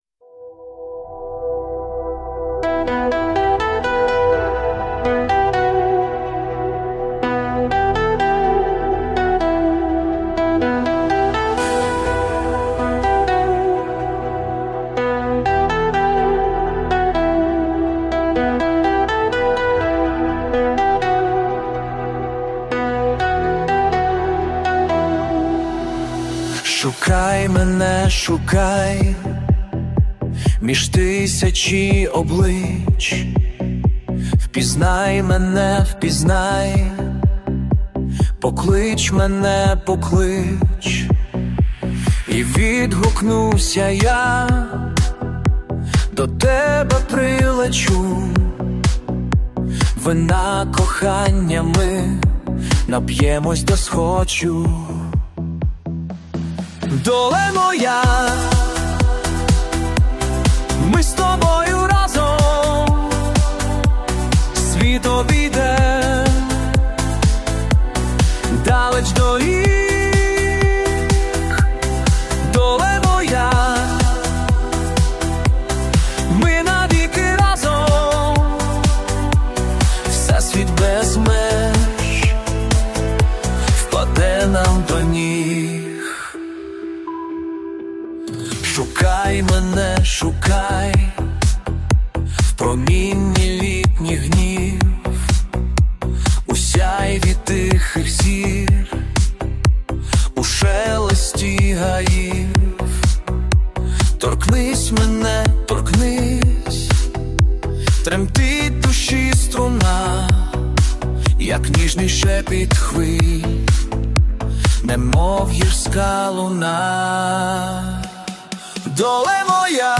СТИЛЬОВІ ЖАНРИ: Ліричний
ВИД ТВОРУ: Авторська пісня